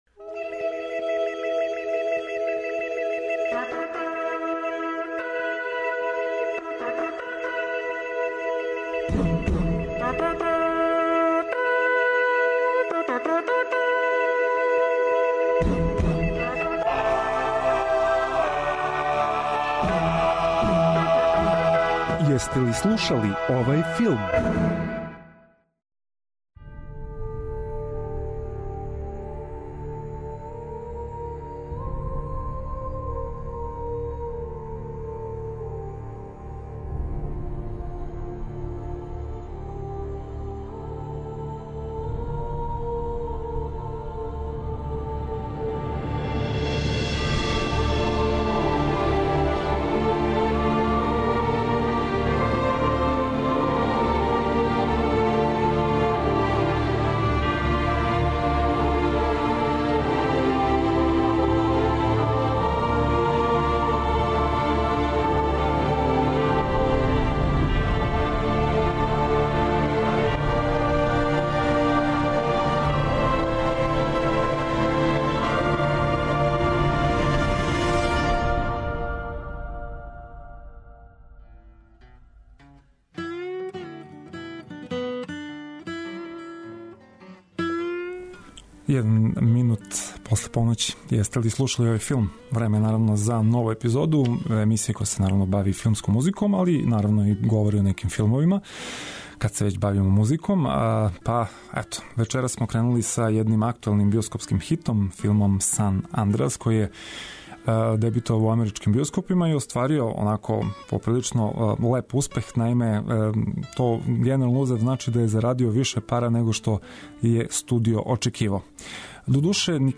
Филмска музика и филмске вести.